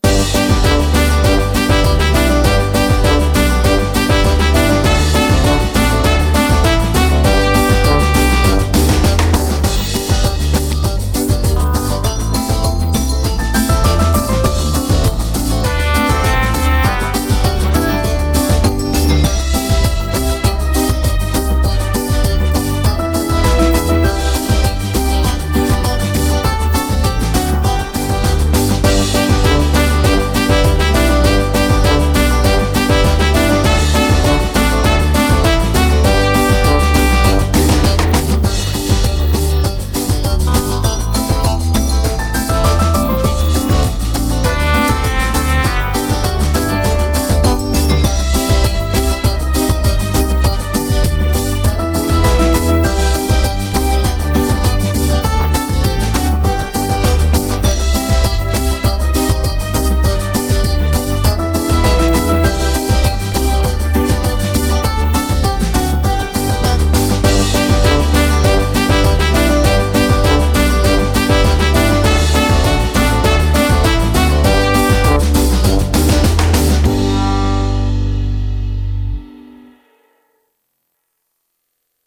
Скачать минус